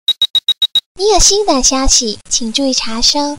新消息声音提醒